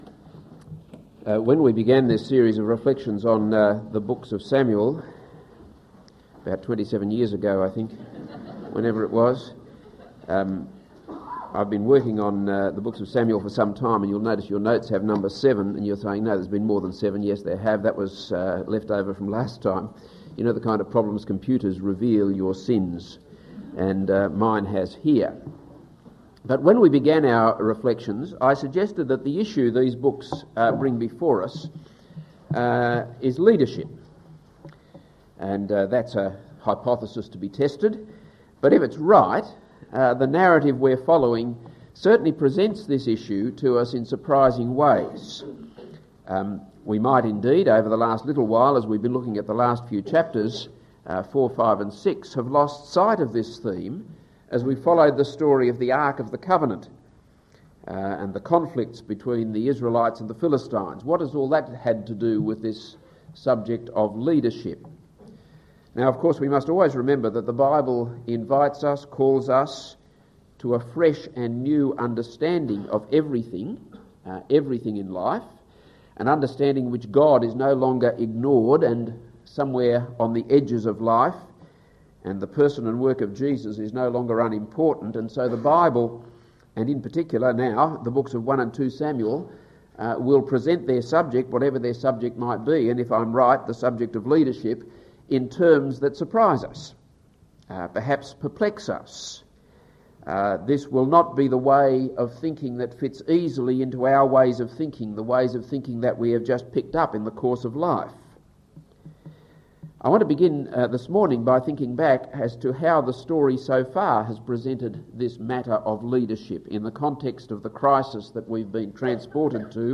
This is a sermon on 1 Samuel 7.